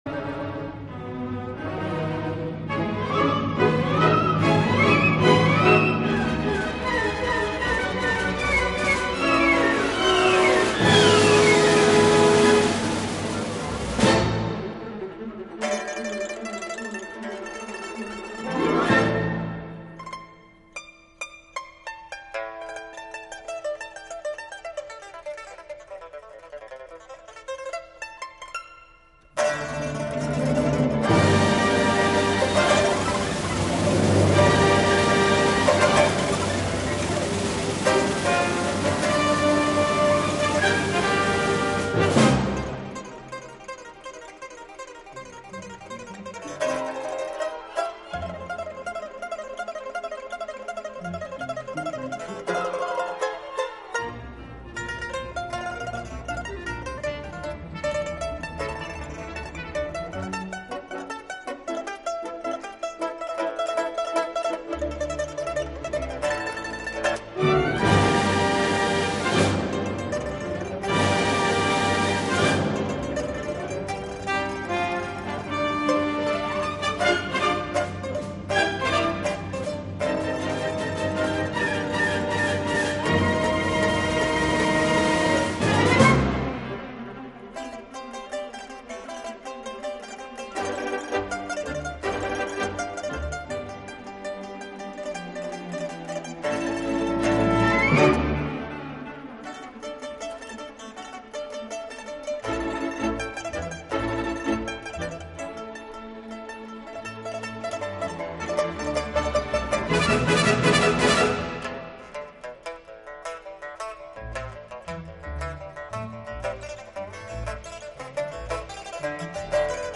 全曲分五个部分,不间段连续演奏:
铜管的引子破雾而出,引出琵琶和乐团互相呼应的节奏旋律,活泼生动逗趣.
风格一变,转成行版的歌咏乐风;乐团和声丰富饱满,和琵琶一起歌咏一段相当优美的旋律.
重现变奏曲首的活泼主题;新机再现,全曲就结束在充满新希望的有力结束中,余韵犹存.